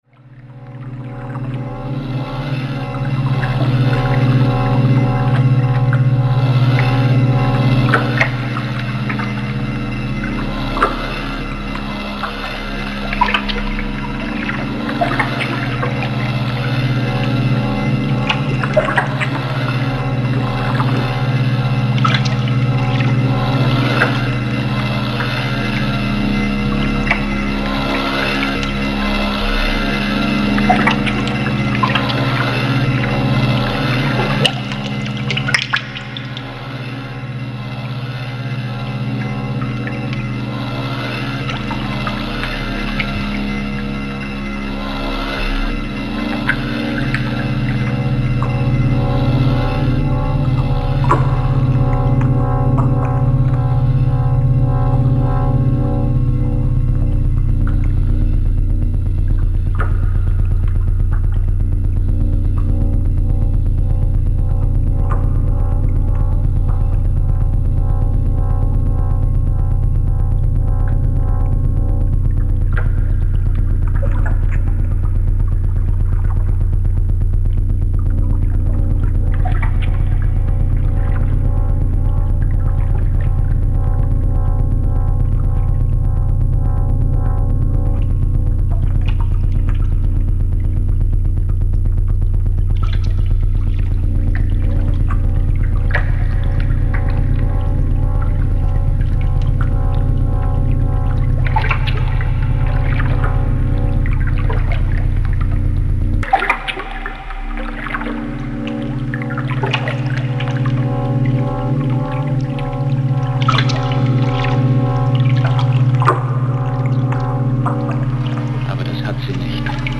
soundscapes